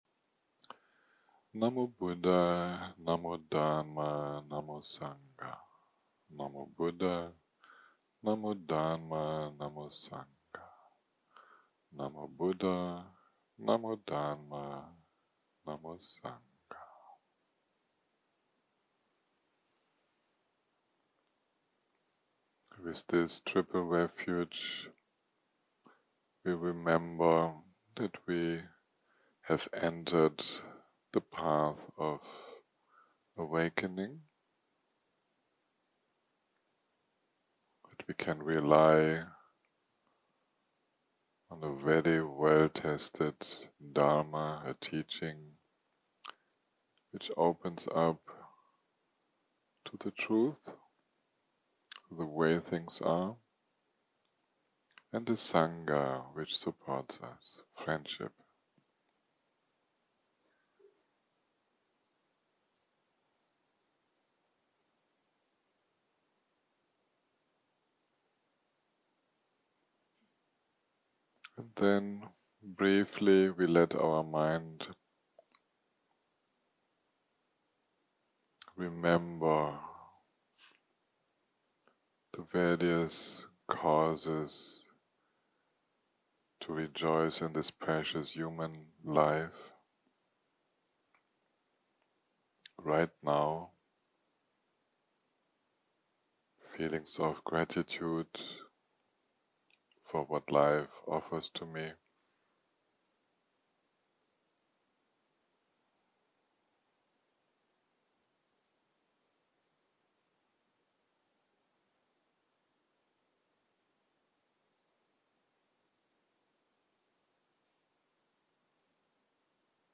יום 4 - הקלטה 14 - בוקר - מדיטציה מונחית - Five elements